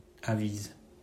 Avise (French: [aviz]
Fr-Avise.mp3